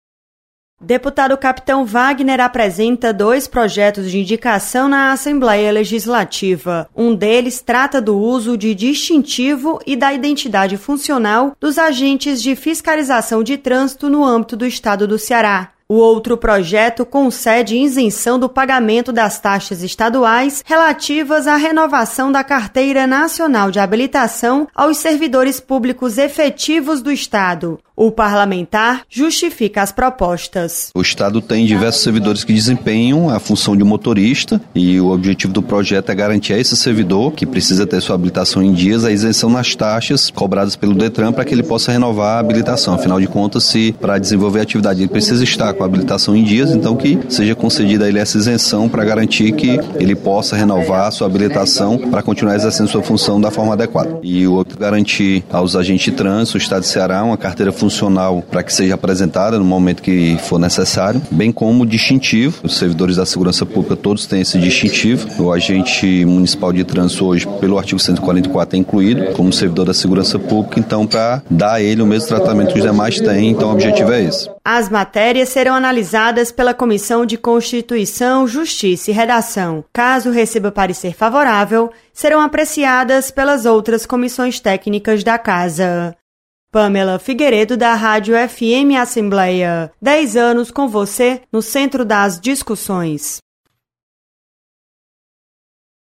Projeto concede isenção da taxa de renovação da CNH para servidores públicos estaduais. Repórter